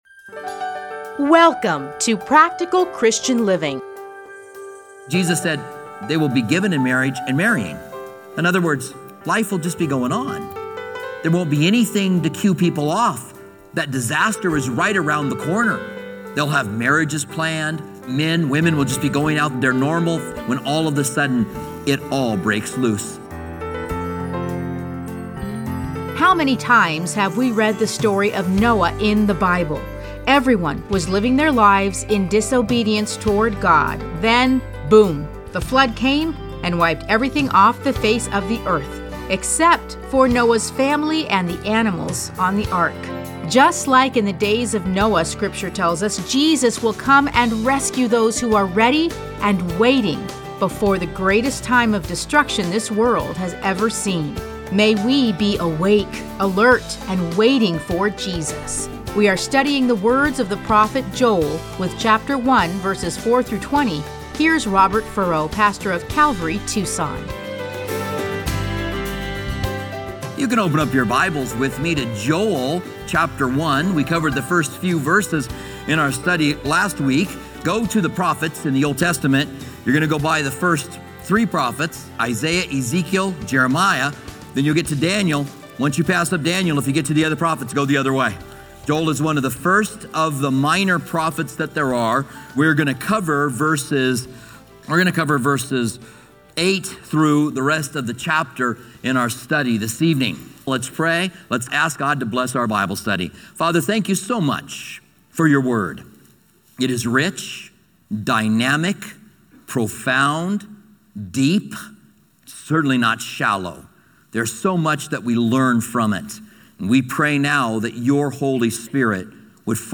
Listen to a teaching from Joel 1:4-20.